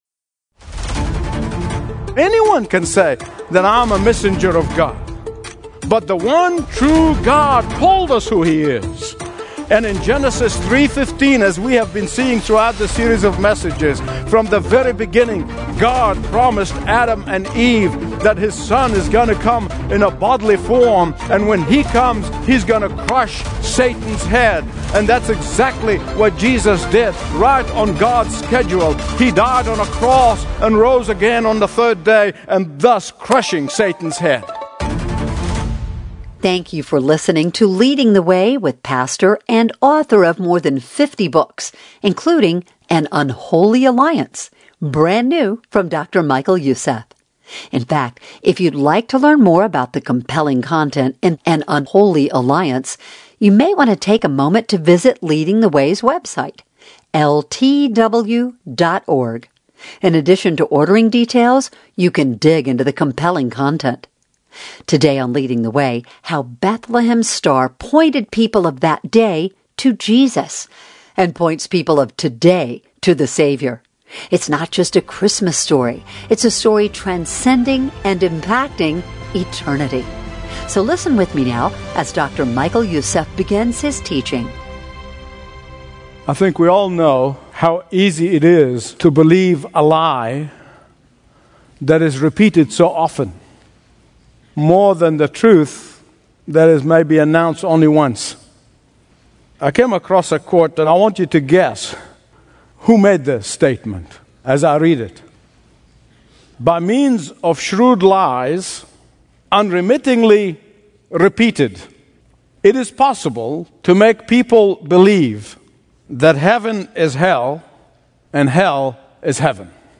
Daily Bible Teachings
Stream Expository Bible Teaching & Understand the Bible Like Never Before.